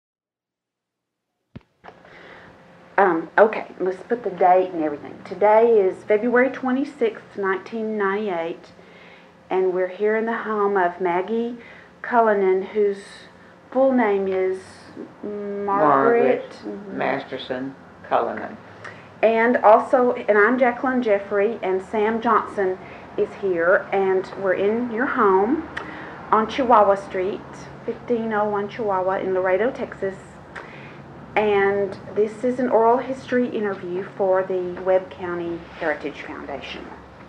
Digitized audio Tape